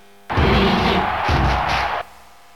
Luigi Crowd Cheer in Dairantou Smash Brothers
Luigi_Cheer_Japanese_SSB.ogg